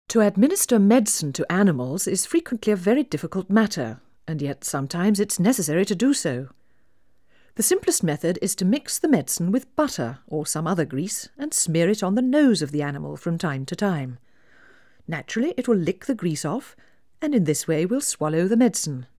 Play 16 dB SNR Noise BMLD
Noise out-of-phase
more noticeable artifacts
"scratching" at headphones